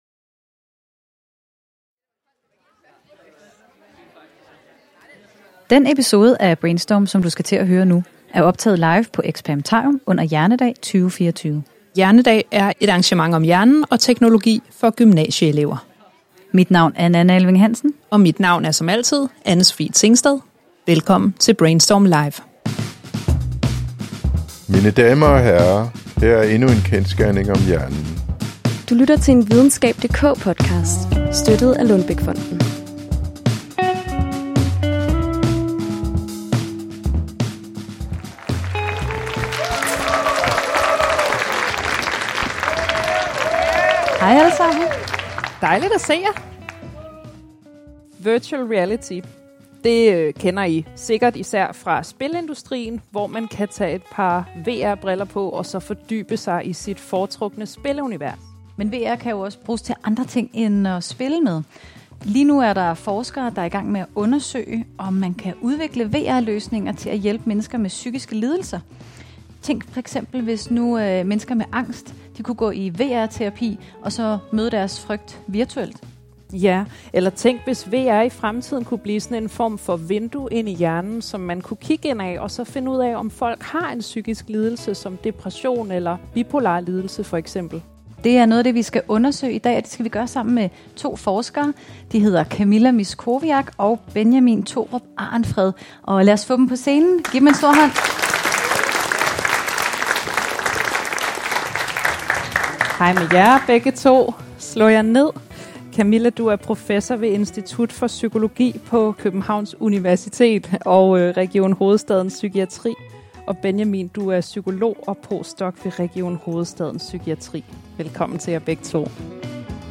I denne særepisode af Brainstorm, der er optaget live på Experimentarium, ser vi nærmere på, hvordan vi i fremtiden kan bruge VR i psykiatrien - og hvor langt vi er med teknologien i dag.
Episoden er optaget foran 125 gymnasieelever i forbindelse med arrangementet Hjernedag 2024.